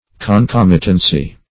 Concomitance \Con*com"i*tance\, Concomitancy \Con*com"i*tan*cy\,